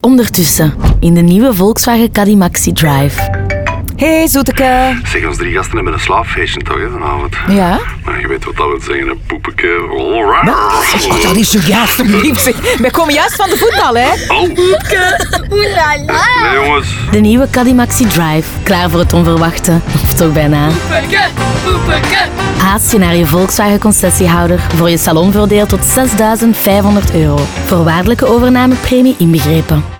Radiospot 1